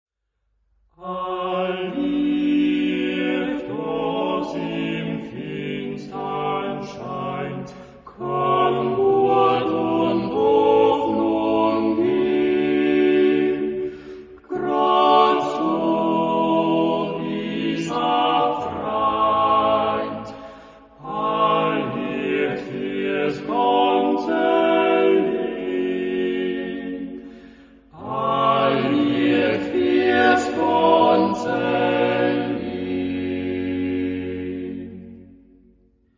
Genre-Style-Form: Secular ; Popular
Mood of the piece: simple
Type of Choir: TTBB  (4 men voices )
Tonality: D major